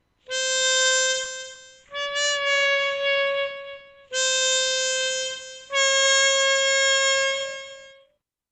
What’s that scooping sound?
Sometimes when we start the 10 hole harmonica, some draw notes can sound slightly out of tune or scooped. This is typical for 2D and 3D.
Scoop-draw1.mp3